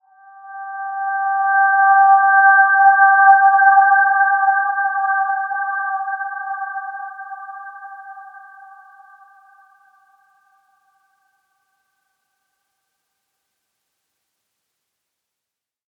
Dreamy-Fifths-G5-p.wav